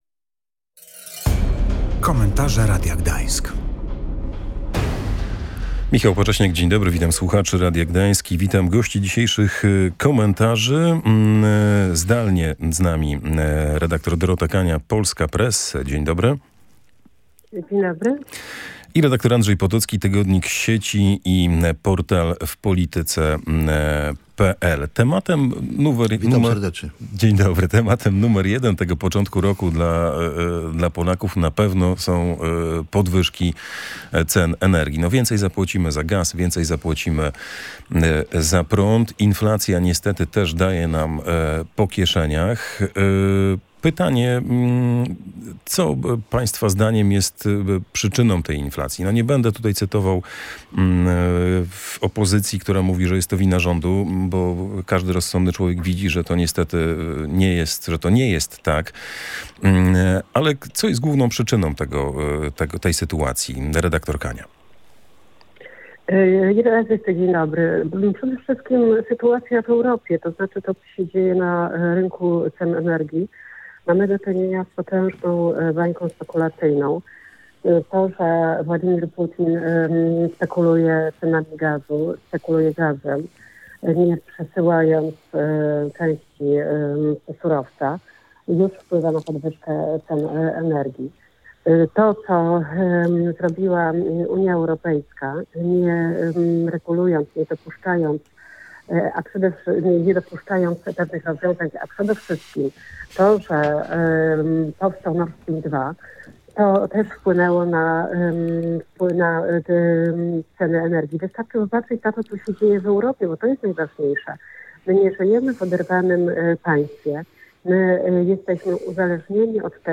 Dyskutowali o tym goście